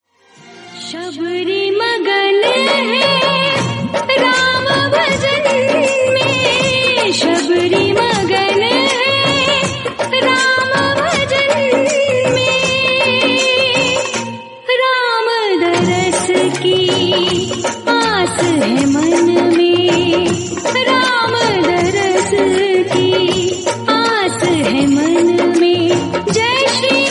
• Calm and peaceful music
• Category: Devotional / Ram Bhajan
• Quality: High Quality / Clear Sound